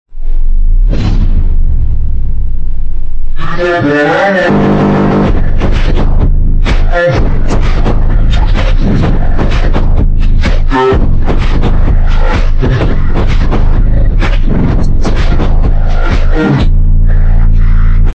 bass-drop-vine-boom-made-with-voicemod-technology_wiBiw17K-timestrech-1.01x.mp3